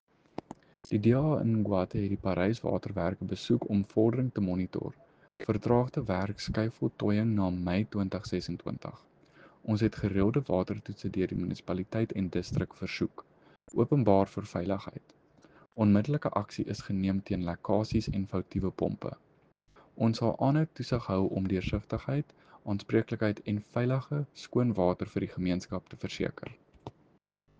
Afrikaans soundbites by Cllr JP de Villiers and